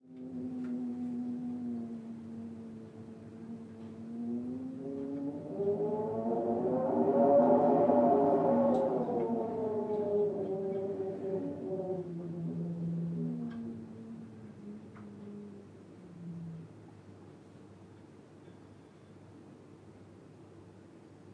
Background Sounds » spooky
描述：Windy night and an owl in Scotland. Not recorded at the same time, but using Samplitude to do the MIX.
标签： spooky wind owl
声道立体声